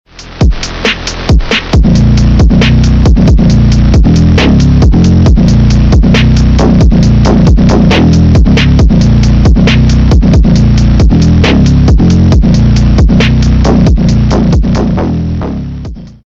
S52 Swapped E30 300KMh+ Top sound effects free download
S52 Swapped E30 300KMh+ Top Speed